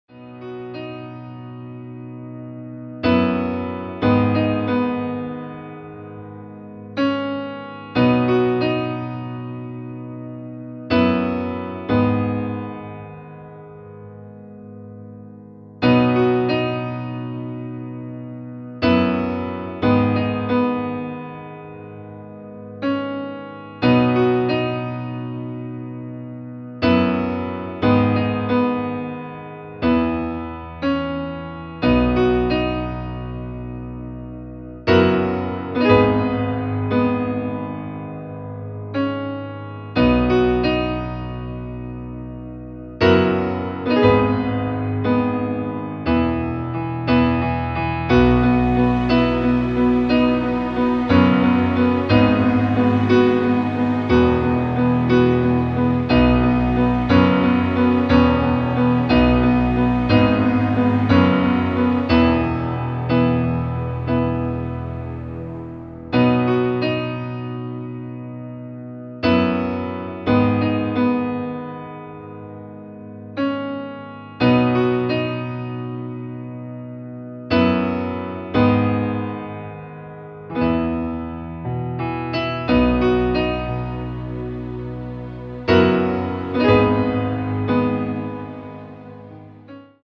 Klavierversion - Flügel
• Tonart:  D Dur, H Dur
• Das Instrumental beinhaltet keine Leadstimme
• Alle unsere DEMOS sind mit einem Fade-In/Out.
Klavier / Streicher